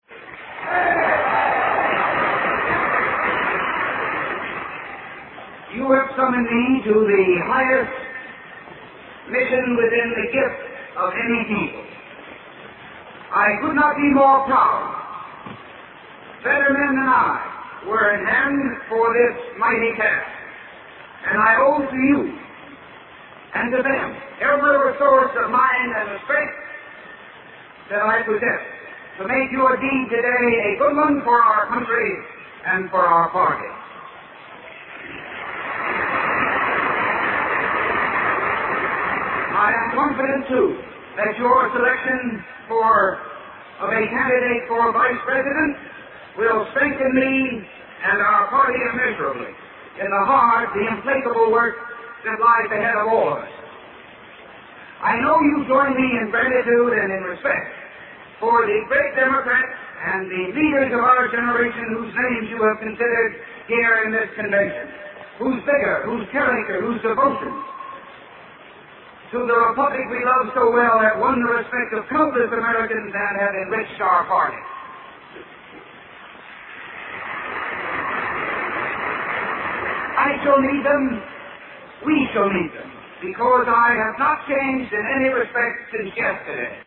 经典名人英语演讲(中英对照):Presidential Nomination Acceptance Speech 3